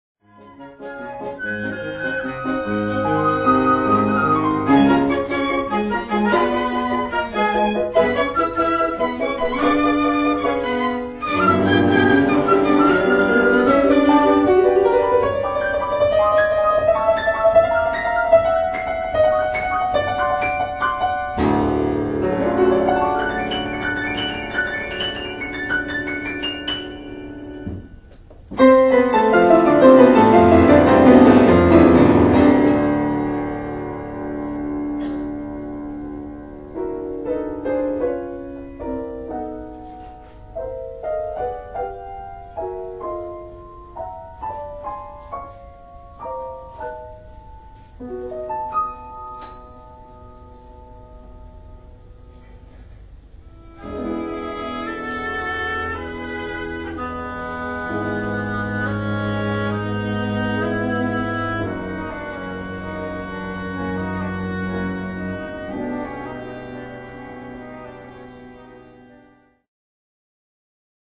音楽ファイルは WMA 32 Kbps モノラルです。
Flute、Oboe、Clarinet、Violin、Cello、Piano